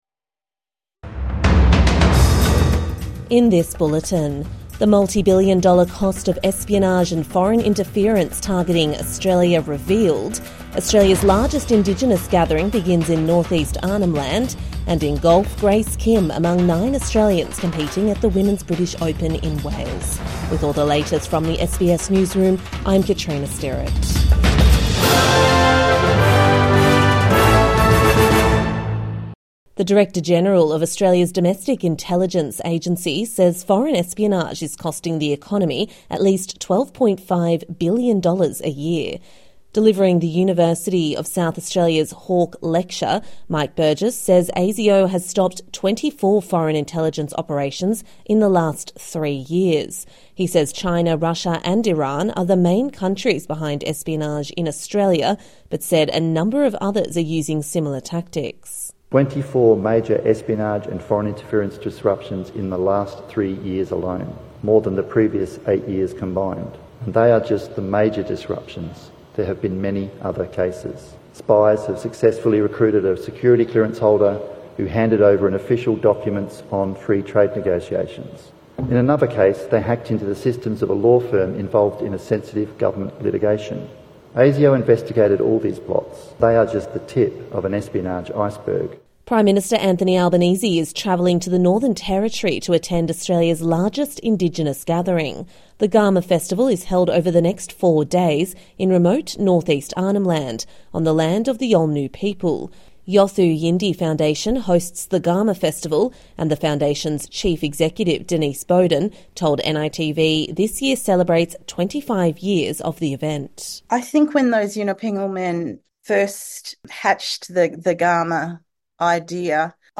The multibillion-dollar cost of foreign espionage revealed | Morning News Bulletin 1 August 2025